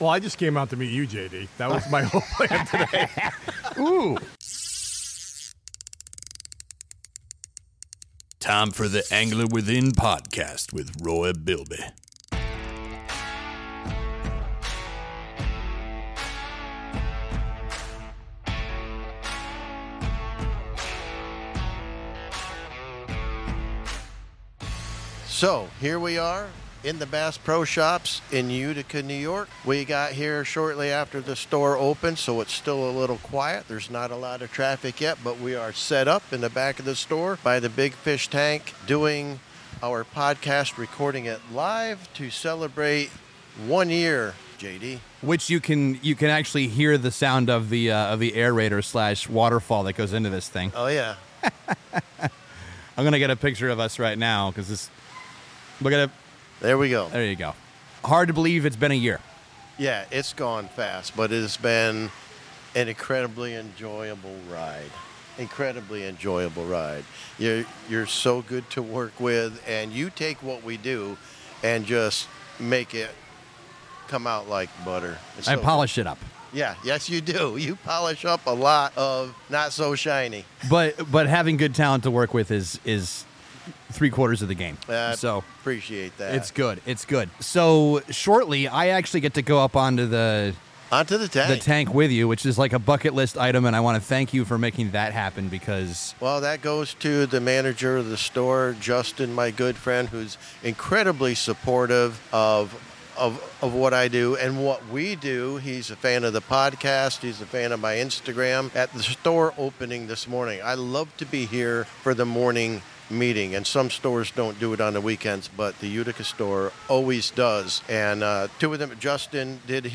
record live at Bass Pro Shops in Utica!